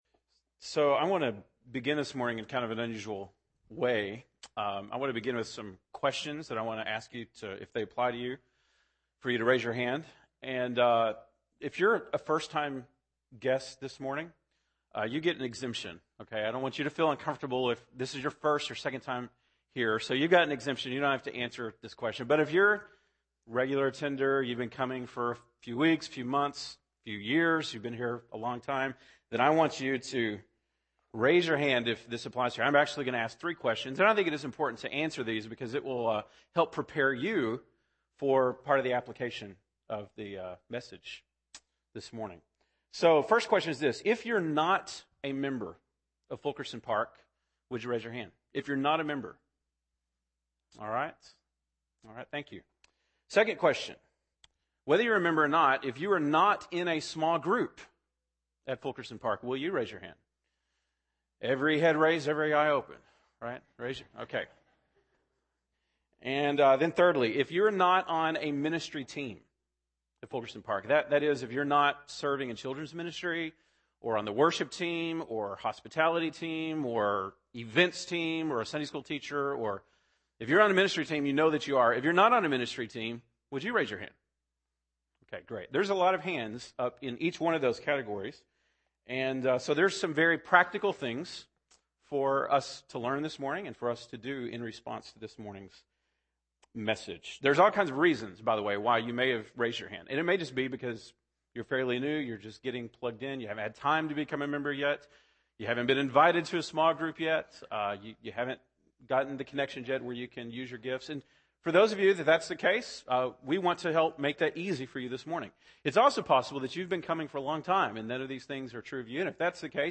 January 30, 2011 (Sunday Morning)